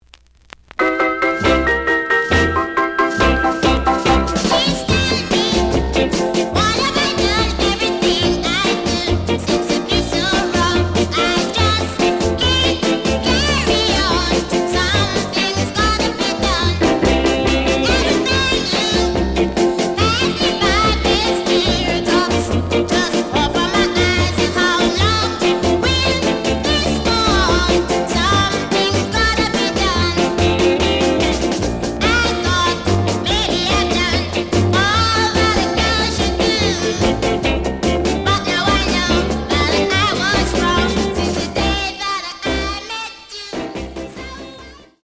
The audio sample is recorded from the actual item.
●Format: 7 inch
●Genre: Ska